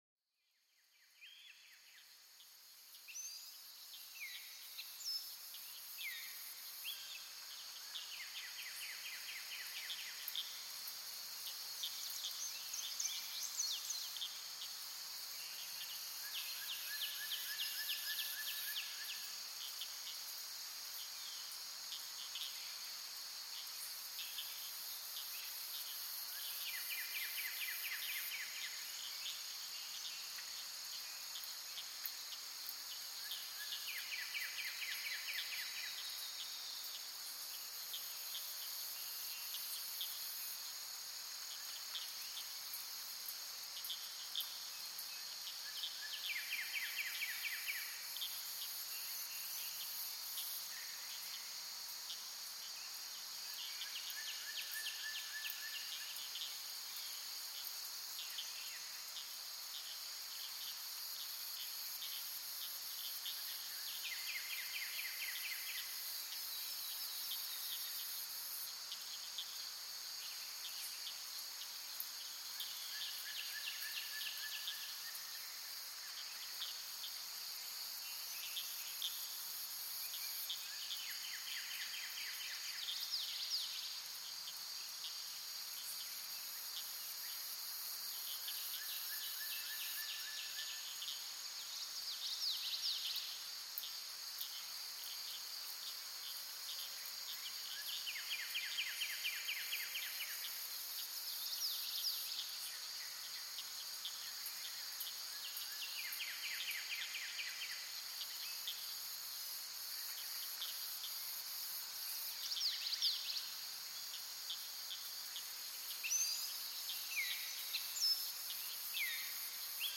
Dans cet épisode spécial, nous vous invitons à vous immerger dans l'ambiance unique d'une forêt paisible, où le chant mélodieux des oiseaux vous accueille dans un monde de tranquillité. Laissez-vous envelopper par le doux bruissement des feuilles et le murmure discret des ruisseaux, créant une harmonie parfaite qui apaise l'esprit et revitalise l'âme.